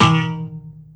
ballExplode.wav